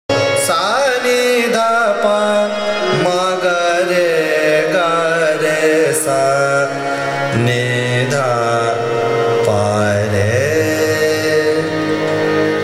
ThaatKhammaj
AvarohaS’ n D P m G R g R S- ‘n ‘D ‘P R